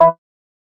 UI-Close.wav